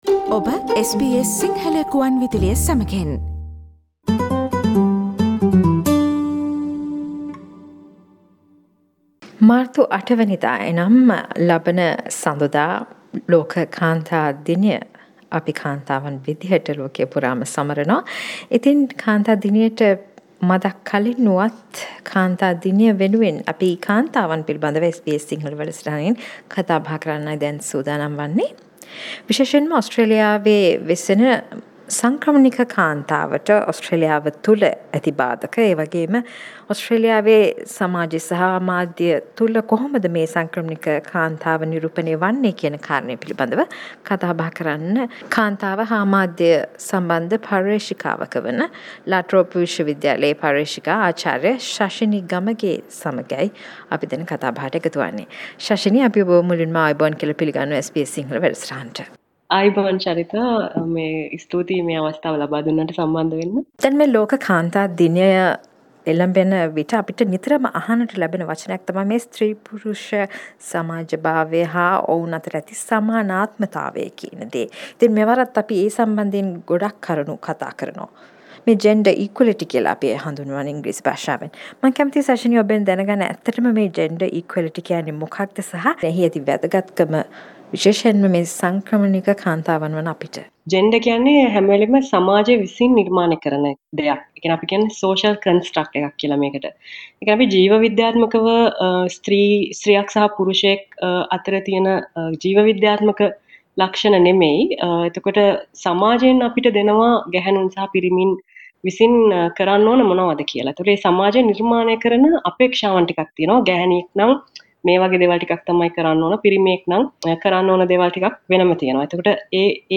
ලෝක කාන්තා දිනය වෙනුවෙන් ඕස්ට්‍රේලියාවේ වෙසෙන ශ්‍රී ලාංකික පර්යේශිකාවක් SBS සිංහල සේවයට පැවසු කරුණු